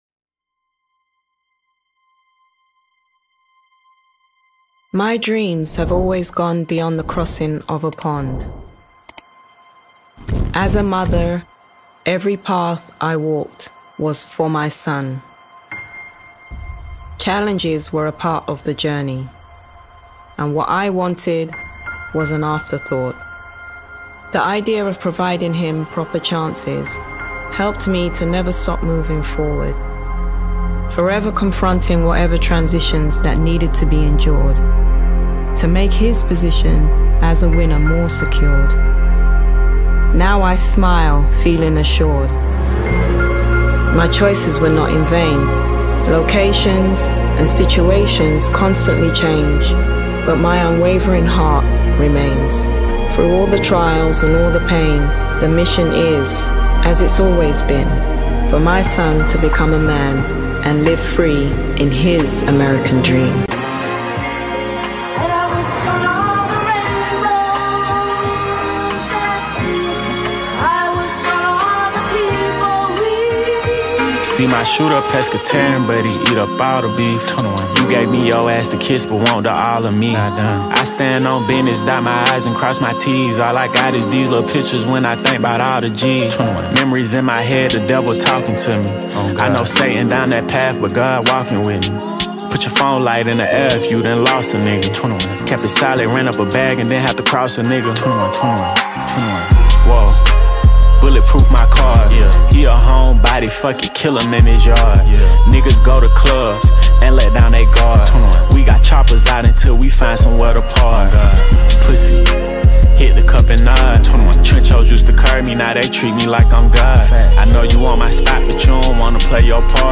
третий студийный альбом британско-американского рэпера